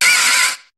Cri de Venipatte dans Pokémon HOME.